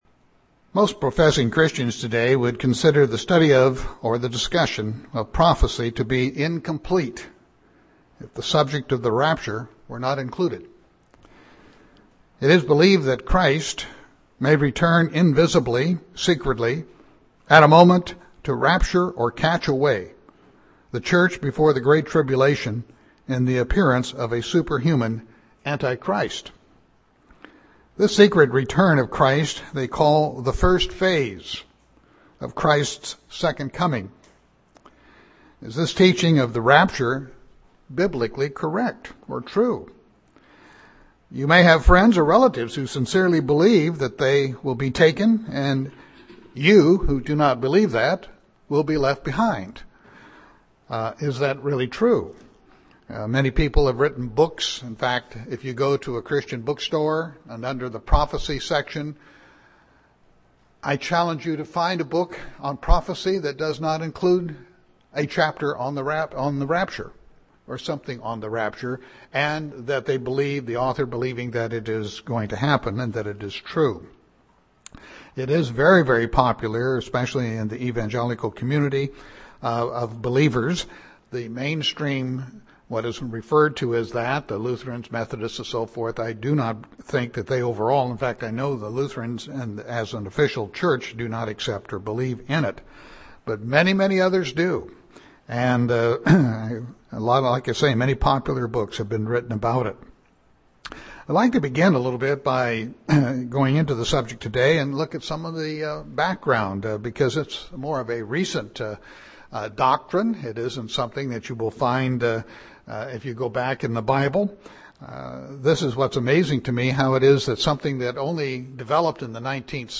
6-9-12 Sermon.mp3